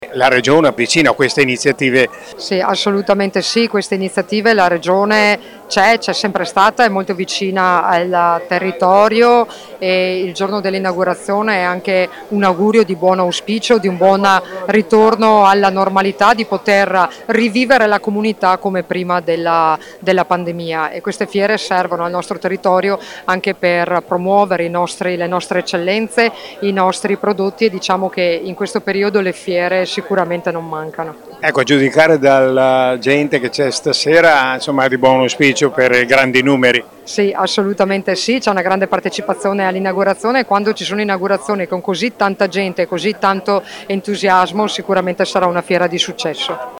Le interviste del nostro corrispondente
Elisa De Berti vicepresidente Regione Veneto